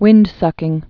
(wĭndsŭkĭng)